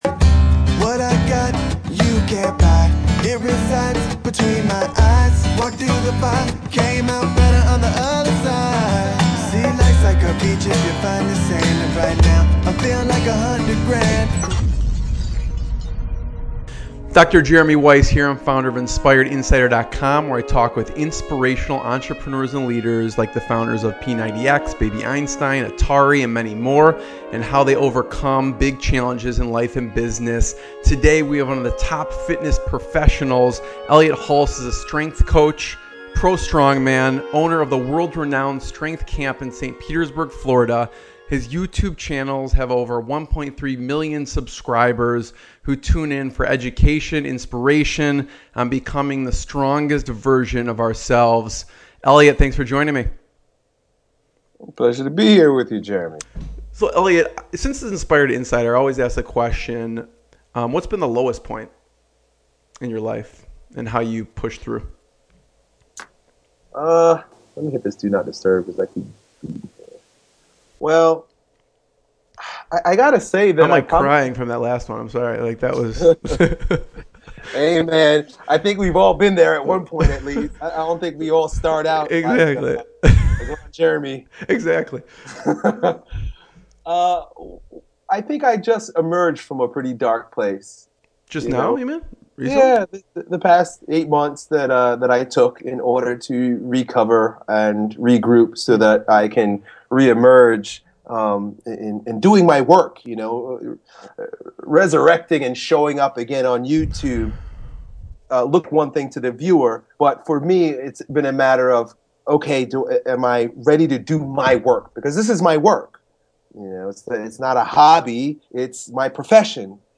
INspired INsider - Inspirational Business Interviews with Successful Entrepreneurs and Founders